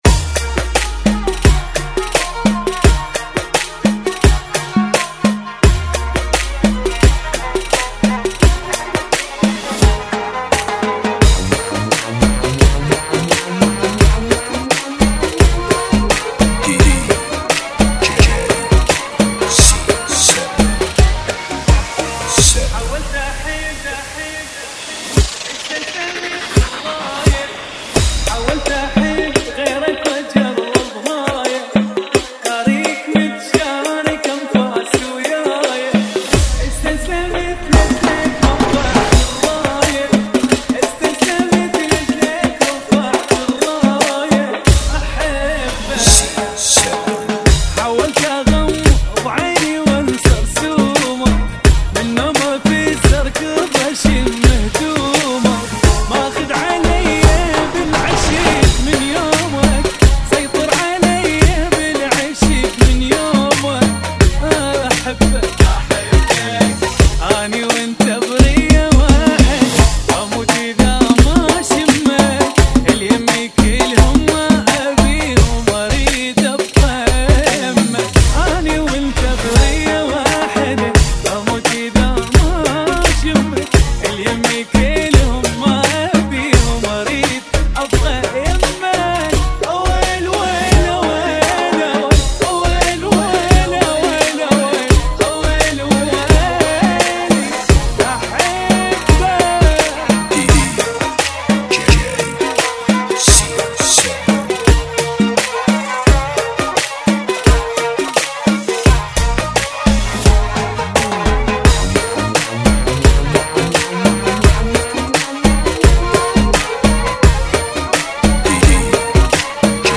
BPM 86